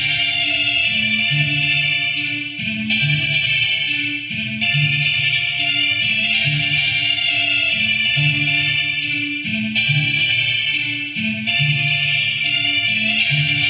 胶带吱吱声
描述：人声录音在磁带上快进的声音。
Tag: 快进 快退 胶带